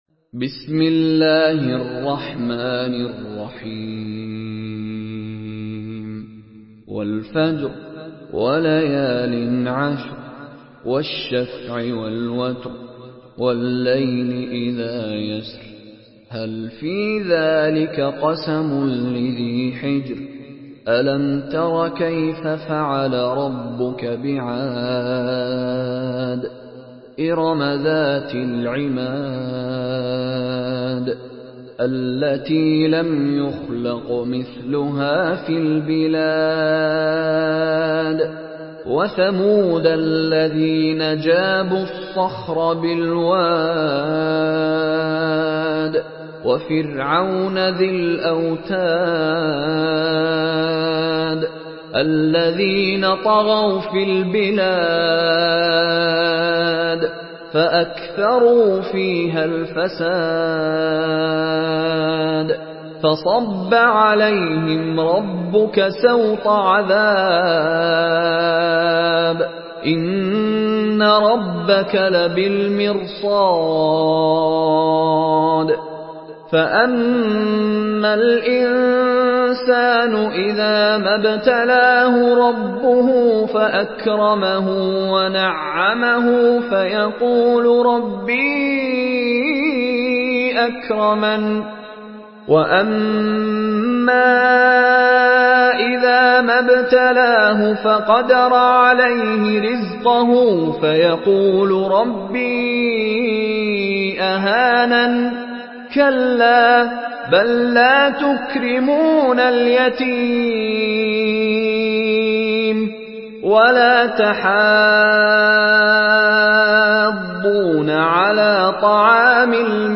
Surah Al-Fajr MP3 in the Voice of Mishary Rashid Alafasy in Hafs Narration
Listen and download the full recitation in MP3 format via direct and fast links in multiple qualities to your mobile phone.
Murattal